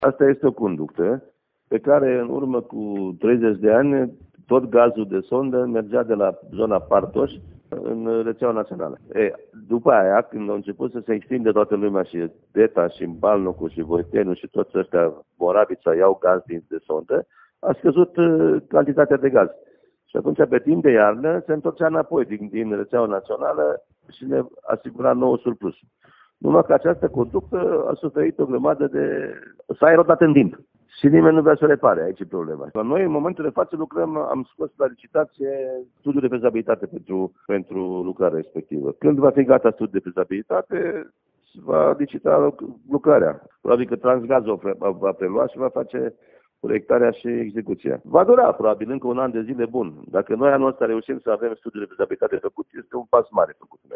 primar-deta-conducta-gaz.mp3